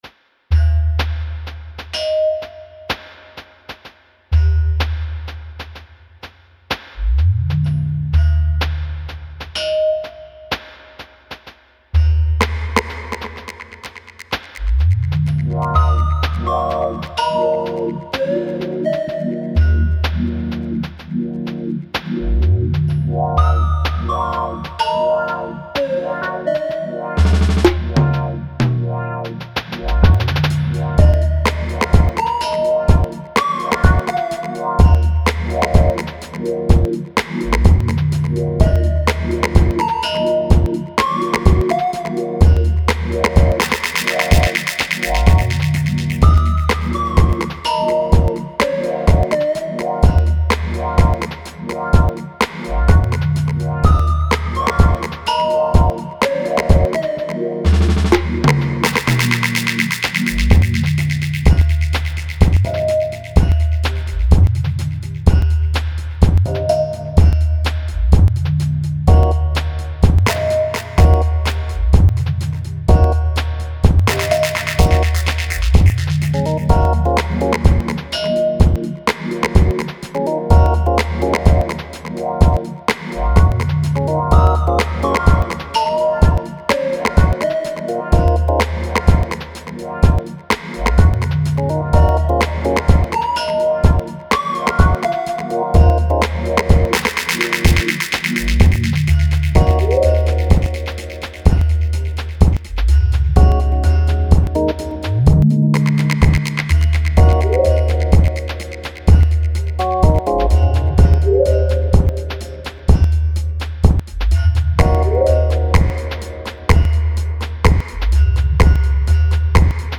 Genre Dub
remix